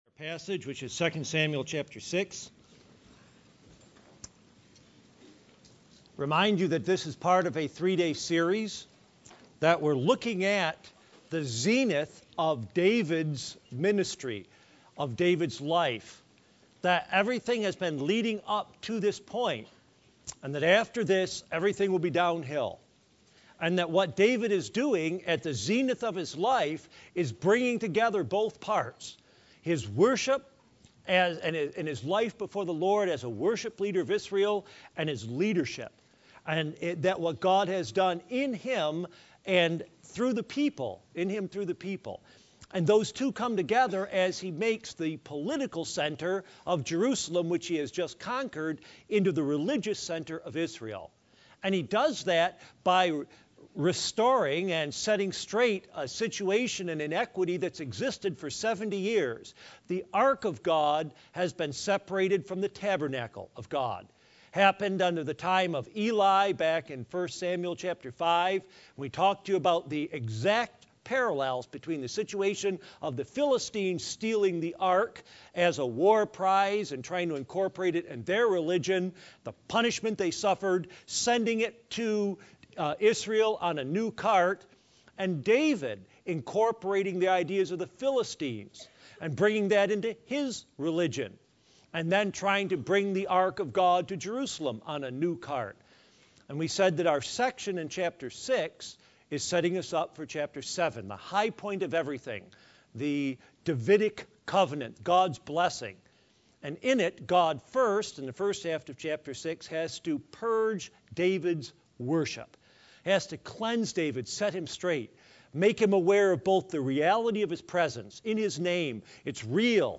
Sermons
Passage: 2 Samuel 6:11-23 Church: BBC Chapel %todo_render% « David and the Ark